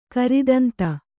♪ karidanta